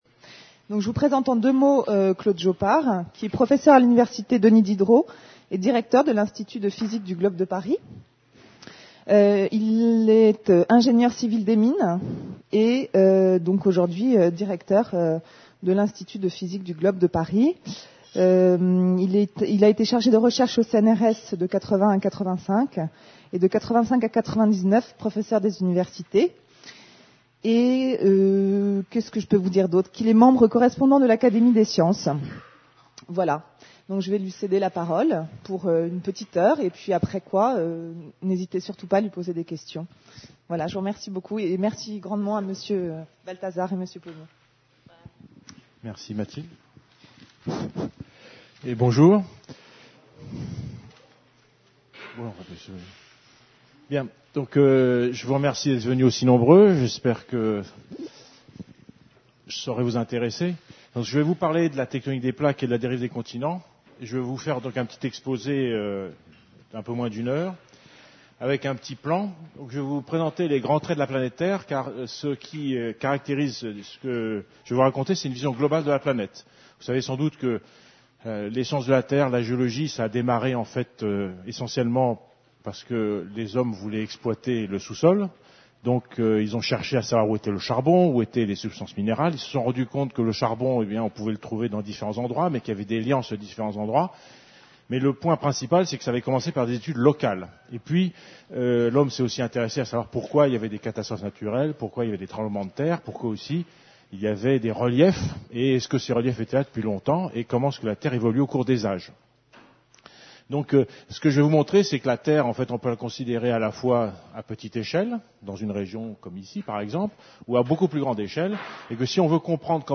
(conférence en audio)